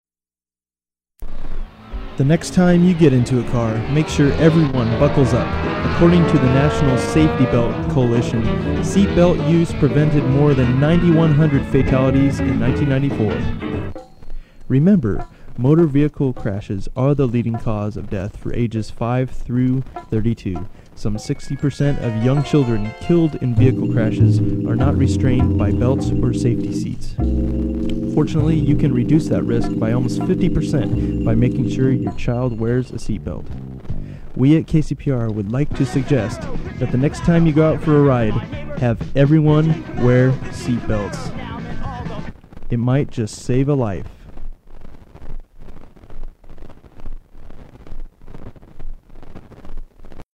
Seatbelt Reminder [announcement]
KCPR-produced announcement which uses a sample from the Dead Milkmen's "Bitchin' Camaro"
• Audiocassette